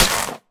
Minecraft Version Minecraft Version 1.21.5 Latest Release | Latest Snapshot 1.21.5 / assets / minecraft / sounds / block / muddy_mangrove_roots / break1.ogg Compare With Compare With Latest Release | Latest Snapshot